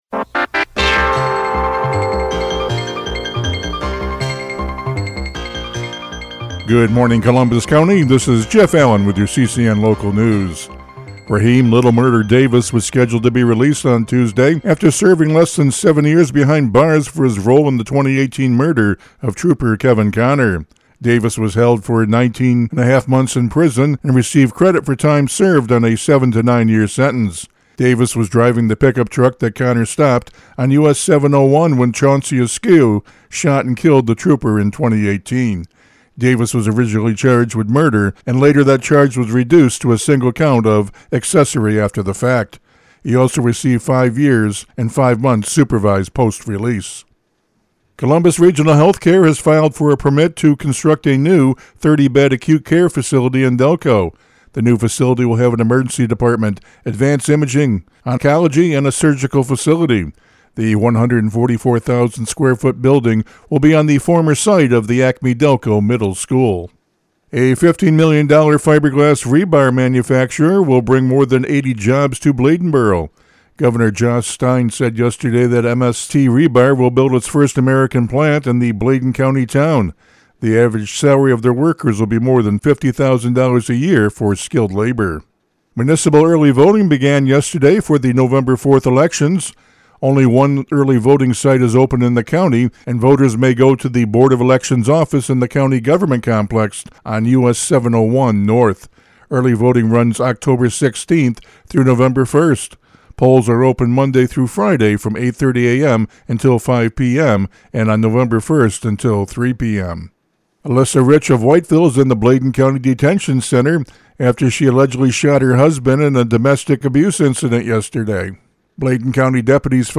CCN Radio News — Morning Report for October 17, 2025